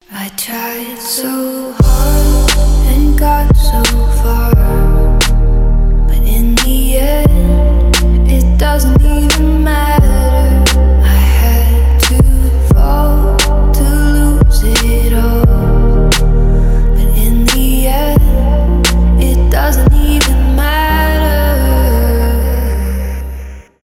ремиксы
красивый женский голос , trap